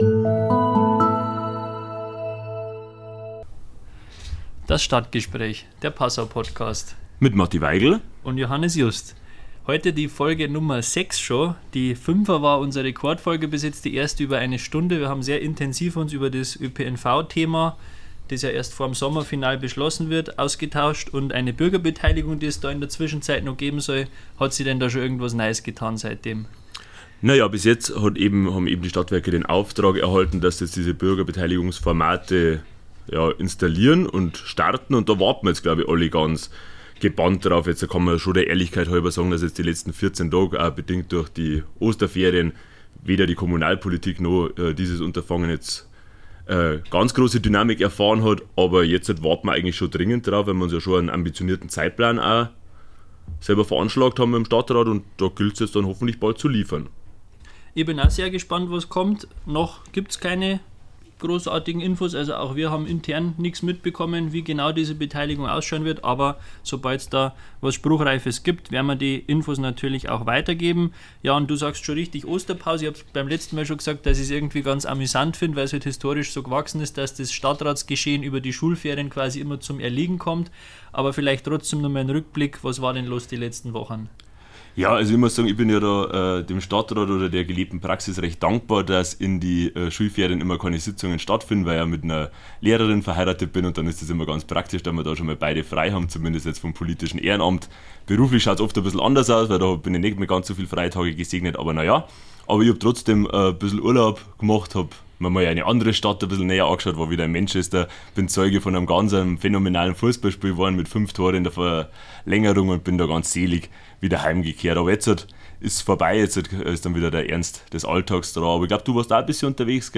Die beiden jüngsten Stadtratsmitglieder, Matthias Weigl (Grüne) und Johannes Just (SPD) sprechen über aktuelle Themen der Lokalpolitk und beziehen Position. Hier geht´s um neue Alkoholverbotszonen, Tempolimit und Semmeltaste